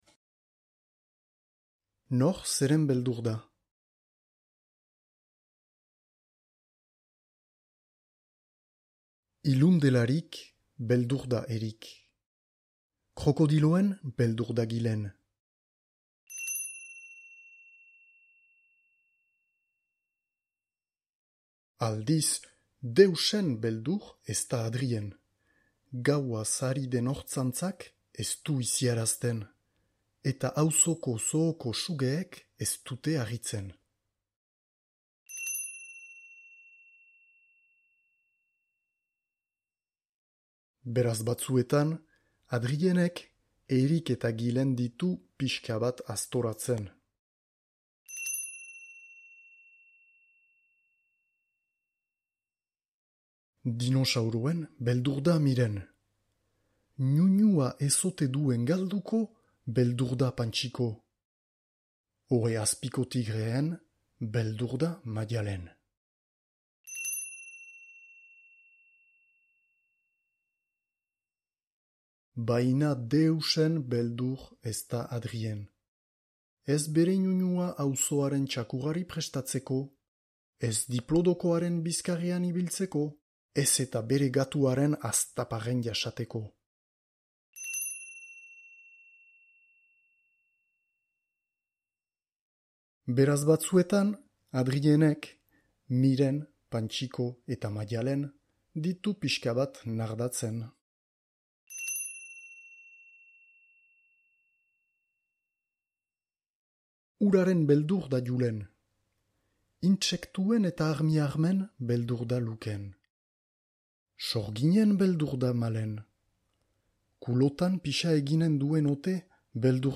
Nor zeren beldur da? - batuaz - ipuina entzungai